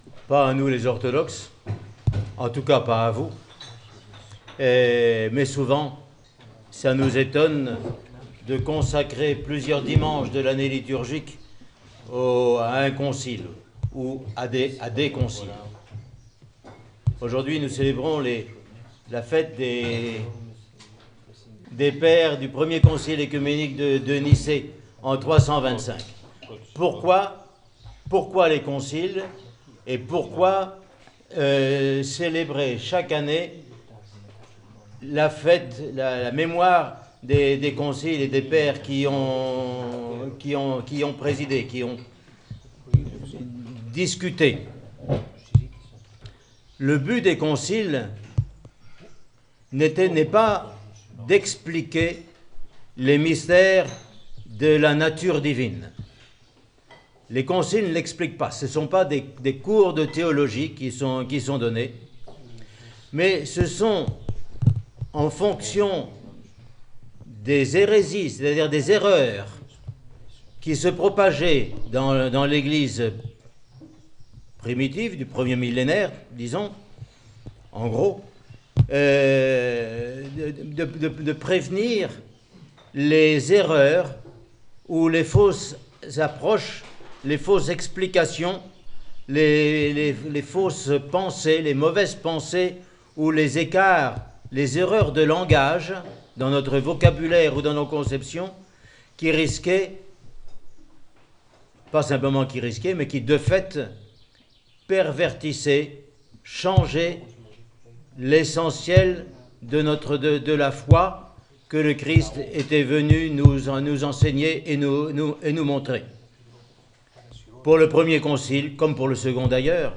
Homélie sur le Concile de Nicée :Monastère de la Transfiguration